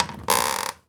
chair_frame_metal_creak_squeak_06.wav